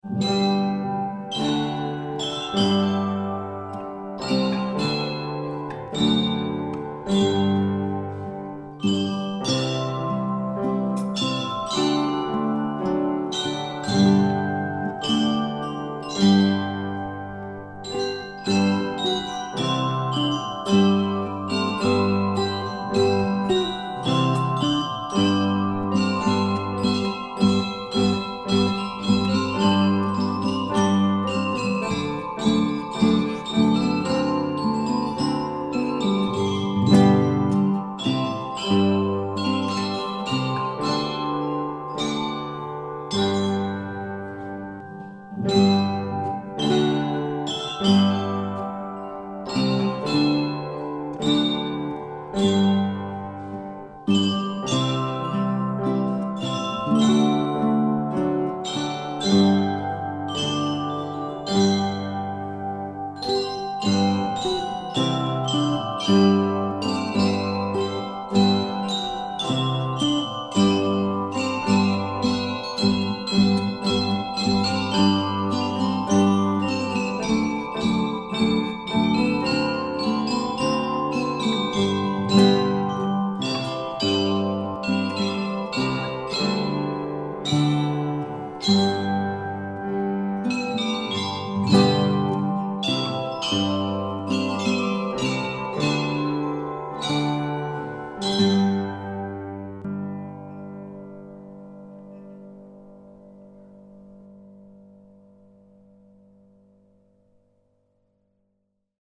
Il suono delle campanine – Le scuole campanarie della FCB
Esegue la Scuola Campanaria di Fondra.